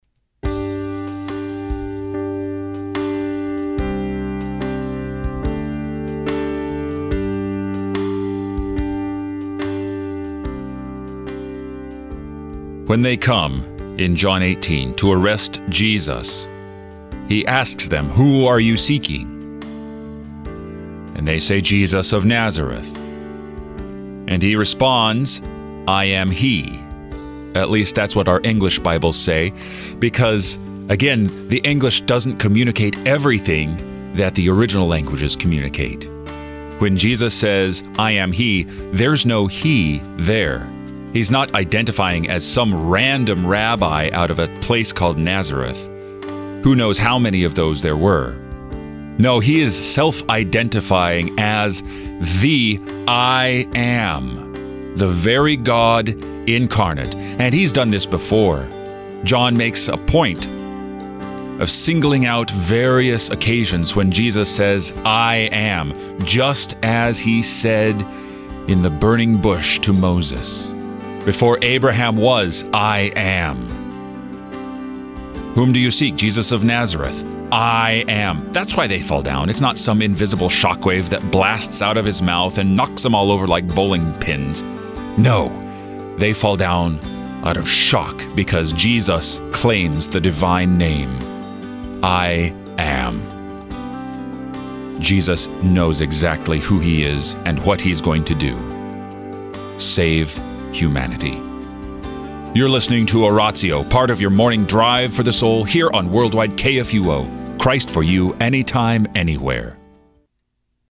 meditations on the day’s scripture lessons